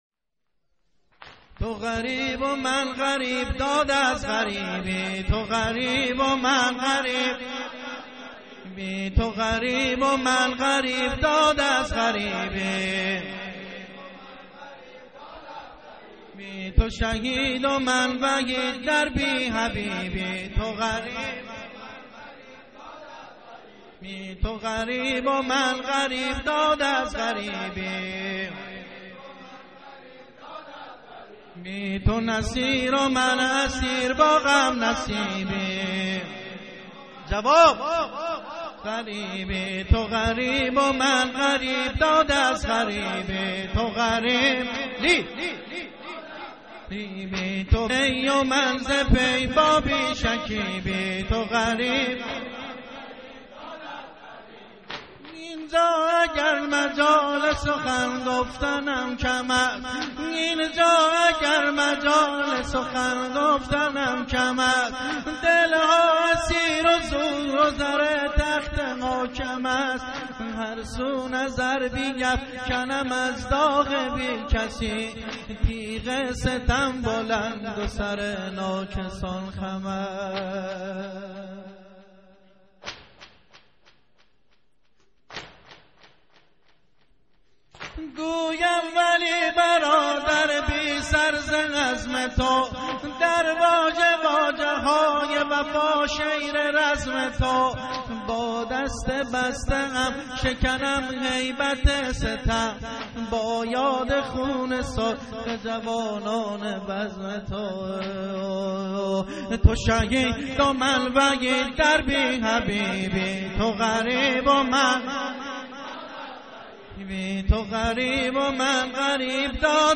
نوحه سینه زنی و زنجیرزنی با سبک بوشهری -( تو غریب و من غریب ، داد از غریبی )